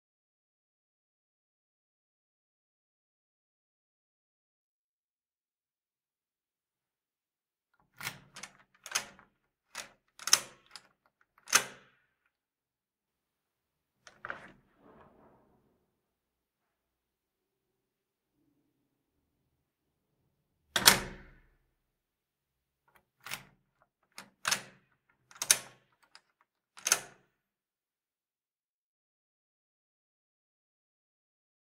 دانلود صدای باز و بسته شدن در زندان 3 از ساعد نیوز با لینک مستقیم و کیفیت بالا
جلوه های صوتی